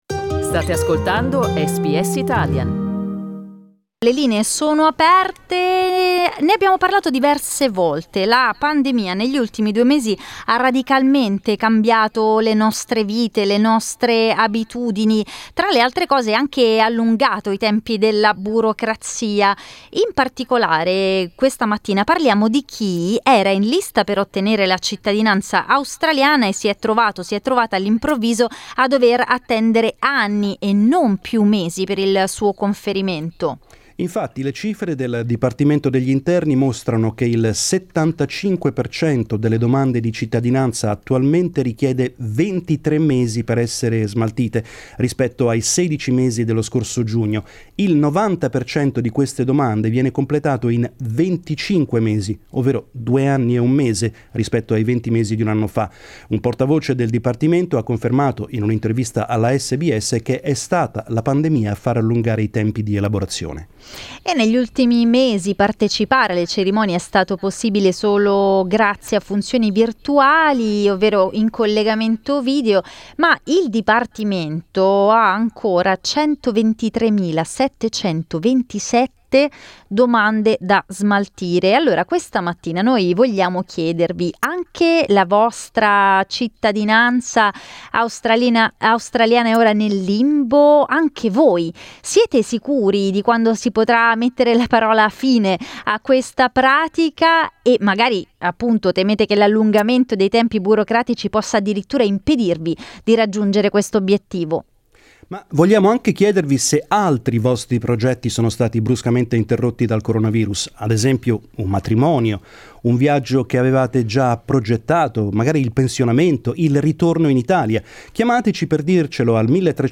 Questa mattina le linee di SBS Italian erano aperte agli italiani che dovevano ricevere quest'anno la cittadinanza australiana, quando la pandemia ha stravolto i loro piani.